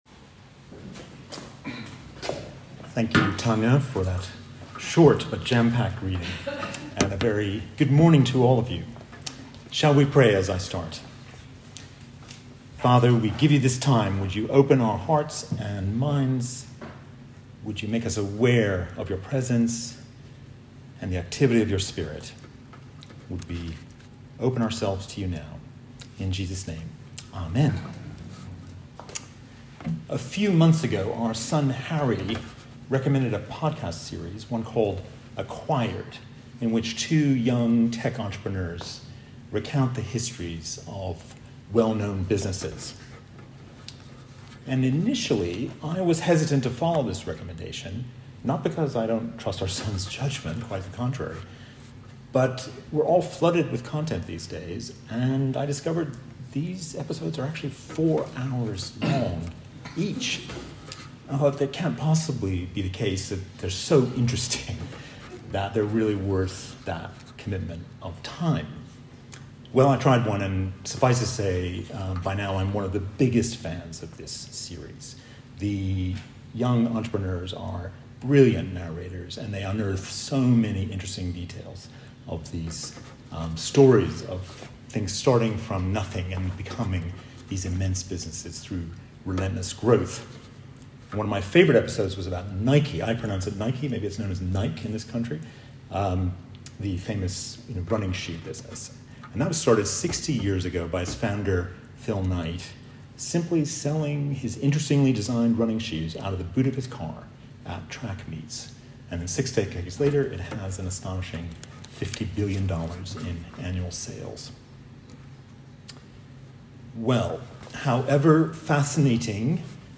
Pentecost was celebrated with a lively service at St Swithun’s in Martyr Worthy on Sunday, when 45 or so people gathered for Valley Worship and the Ark.